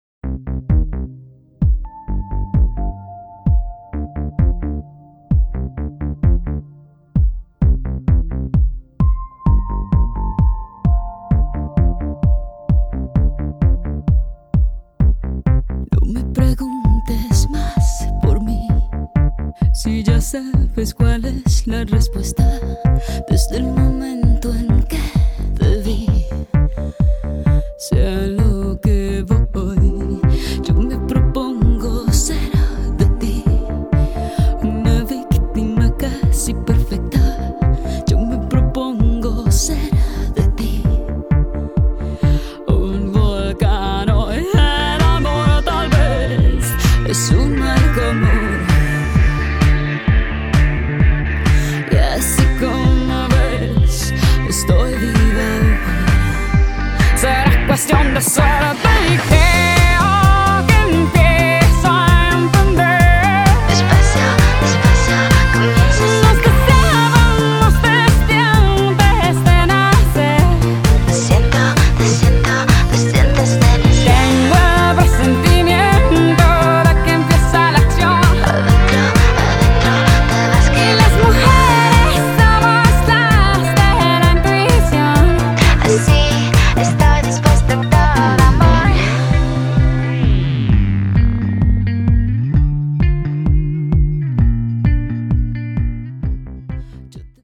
BPM130
Audio QualityPerfect (High Quality)
an upbeat song that fits the dancing game theme.
AUDIO: Cut from the CD!